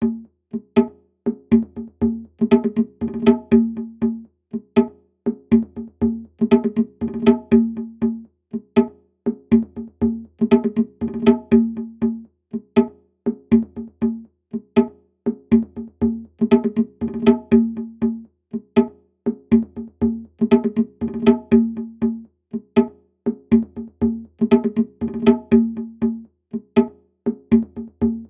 Drum Modelling Examples
congaDjembeHybrid
congaDjembeHybrid.mp3